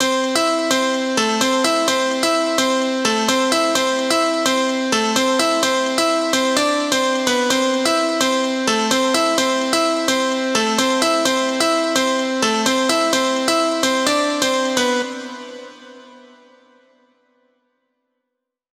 VDE 128BPM Notice Melody 1 Root C.wav